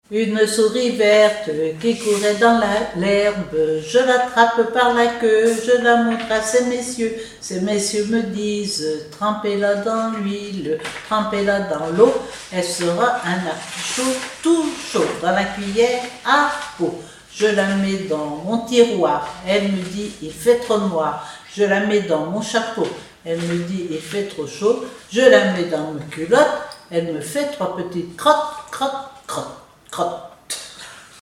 formulette enfantine : amusette
comptines et formulettes enfantines
Pièce musicale inédite